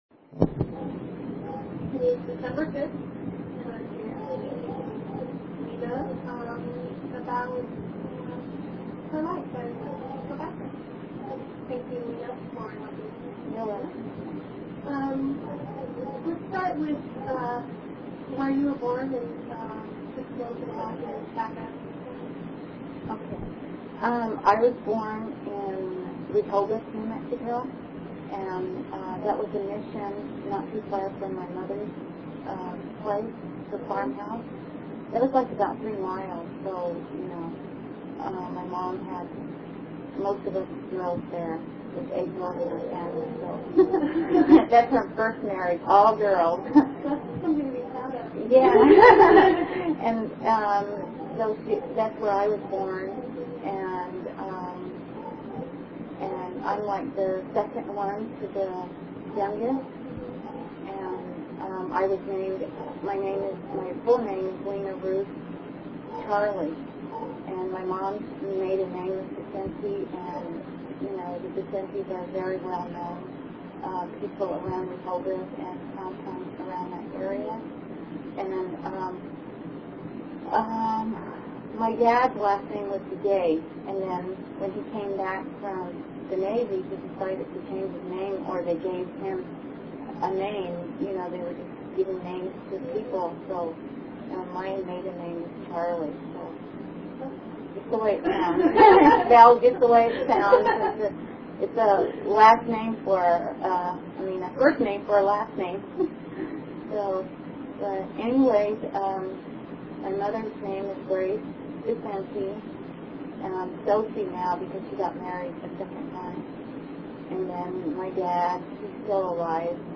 This single, short interview was conducted in conjunction with a class in American Indian Studies, a program in which both the native narrator and the Anglo interviewer were enrolled.